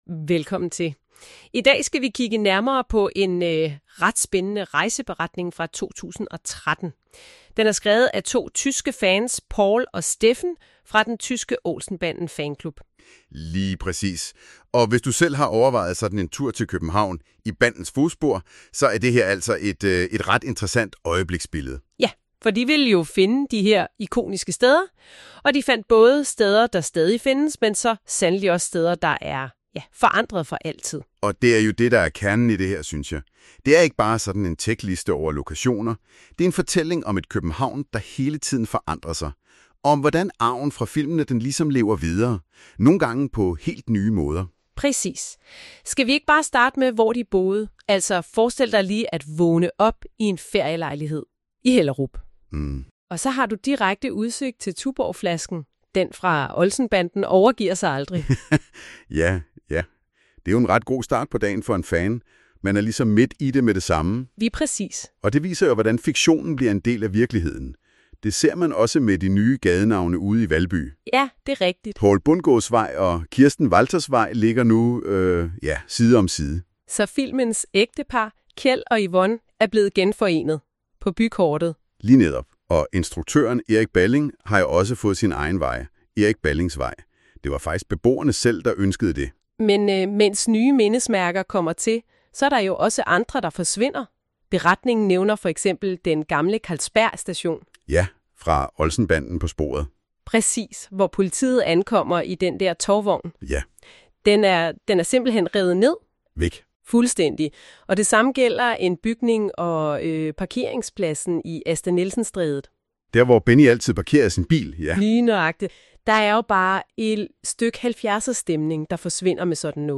Lydresumé i podcastformat
MP3 (AI-genereret lydindhold)